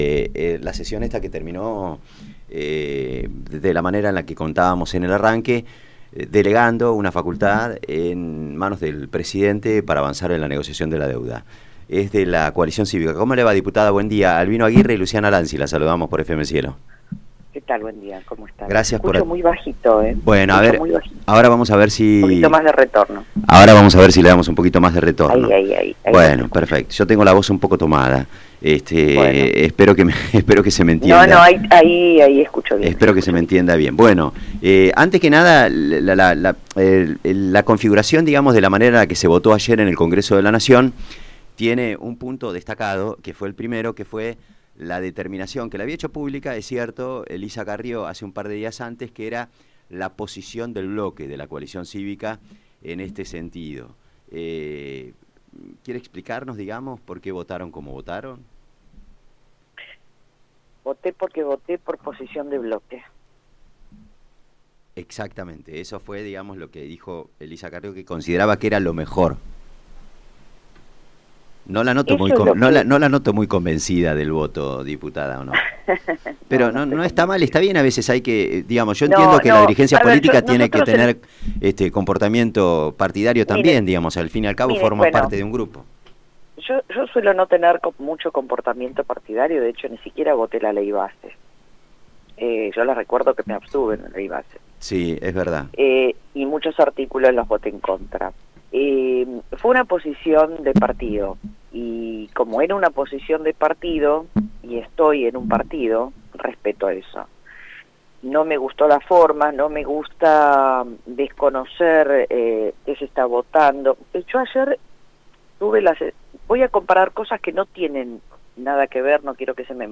Hablamos con Mónica Frade, diputada de la Coalición Cívica por la Provincia de Buenos Aires sobre la votación en el recinto.